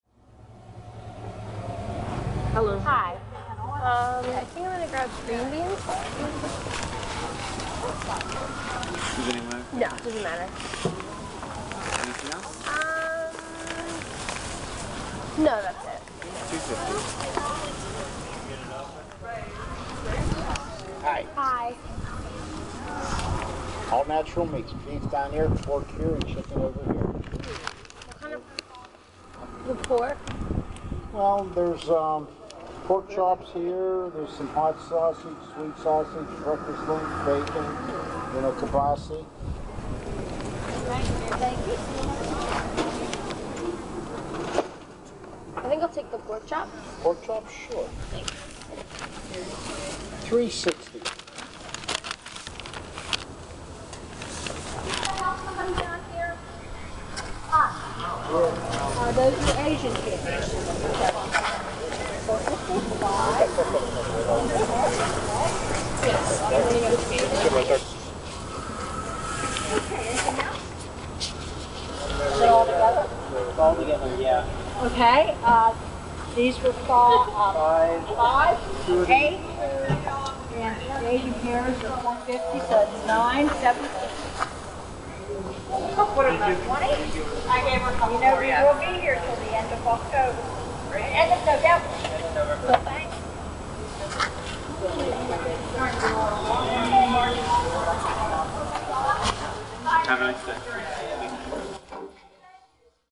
Proposed Soundscape - Sennott Street Farmer's Market
There’s always a certain buzz in the farmers market.
At the same time, the market’s locale is only a block from Forbes Ave, so the traffic and commotion of the city can still be heard in the background.
There’s chatter about peaches and squash, but the roar of the bus engine on Forbes can be heard in the background.
Rough Soundscape
I think it would more interesting to capture the other sounds of the market, like coins rumbling, footsteps and plastic bags.
This is why I plan to record the market sounds and city background sounds more than the conversations themselves.